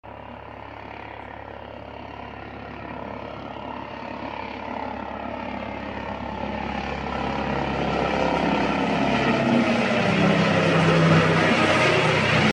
Airbus H145M, Serbian Air Force. Sound Effects Free Download
Airbus H145M, Serbian Air-Force.